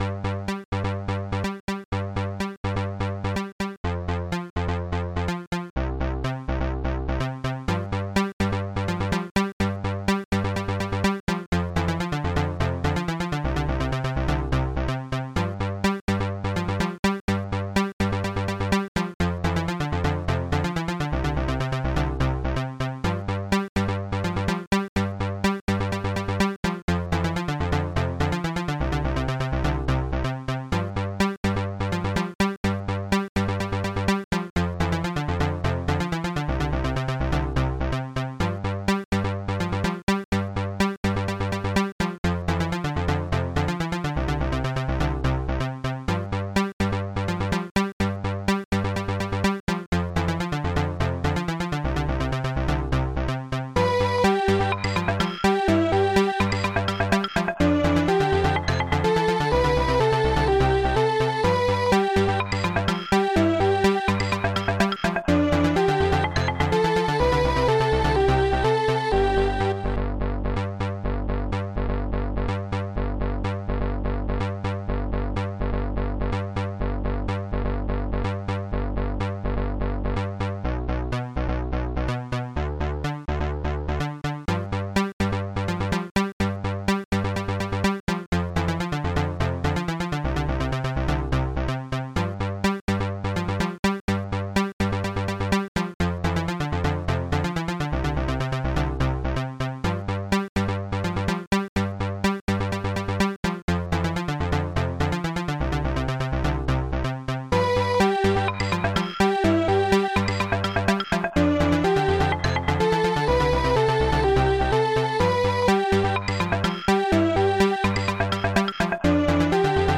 SoundTracker Module  |  1996-03-11  |  86KB  |  2 channels  |  44,100 sample rate  |  3 minutes, 12 seconds
Protracker and family
st-01:korgbass
st-01:strings6
st-01:claves